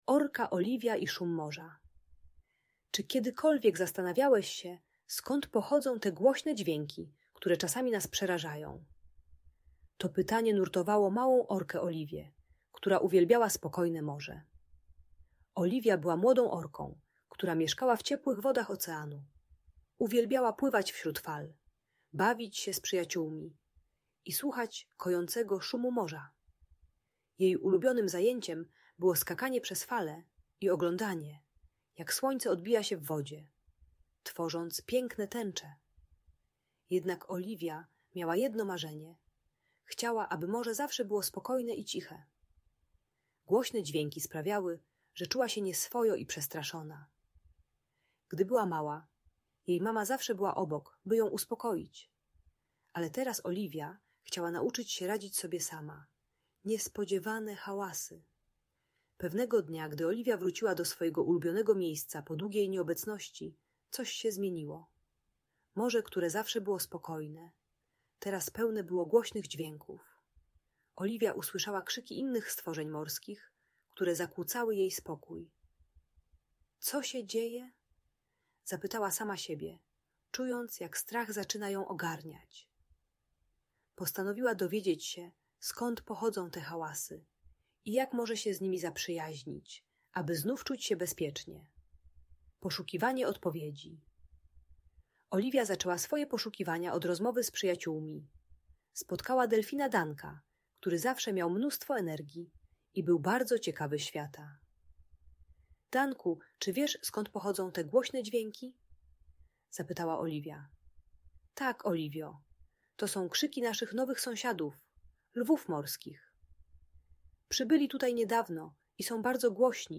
Opowieść o Orce Oliwii i Szumie Morza - Audiobajka dla dzieci